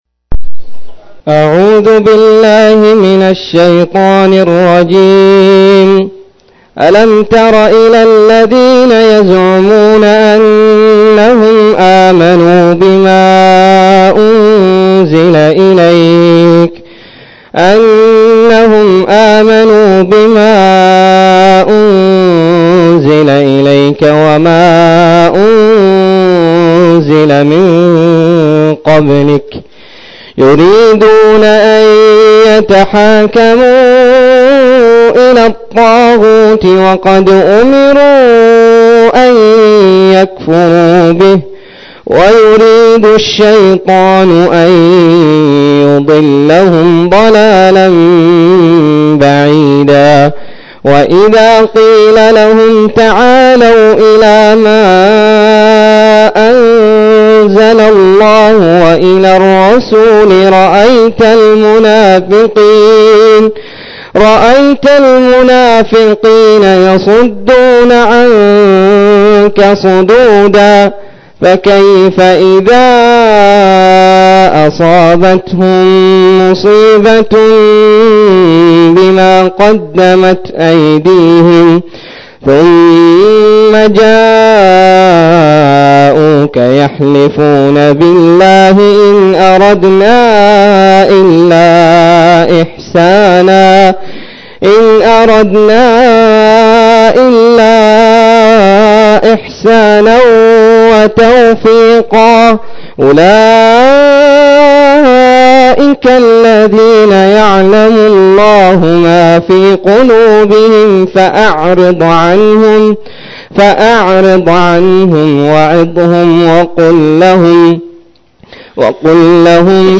092- عمدة التفسير عن الحافظ ابن كثير رحمه الله للعلامة أحمد شاكر رحمه الله – قراءة وتعليق –